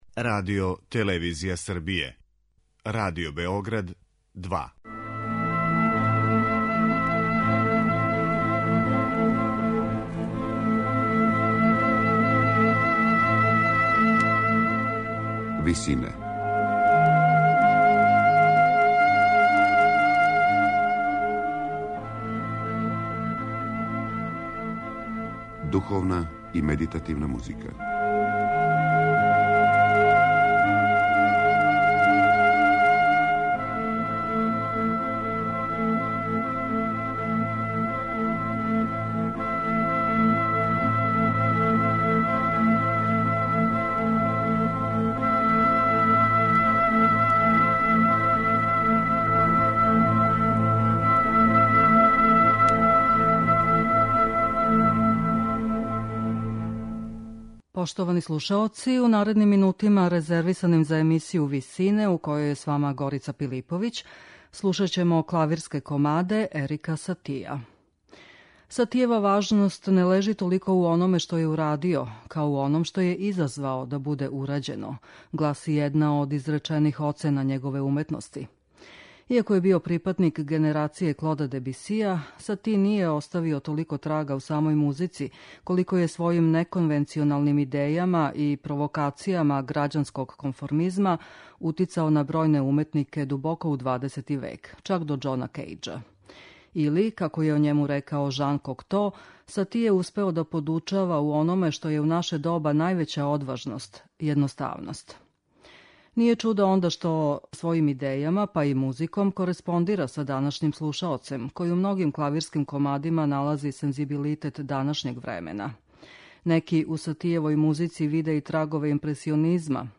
Сатијеви клавирски комади